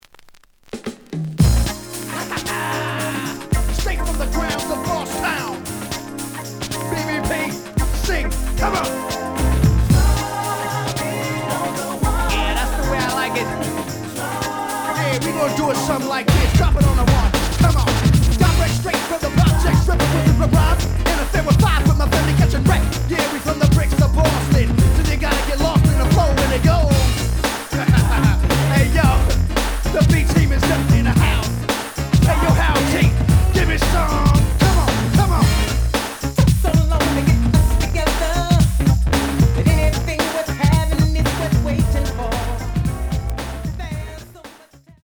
The audio sample is recorded from the actual item.
●Genre: Hip Hop / R&B
Slight edge warp. But doesn't affect playing. Plays good.)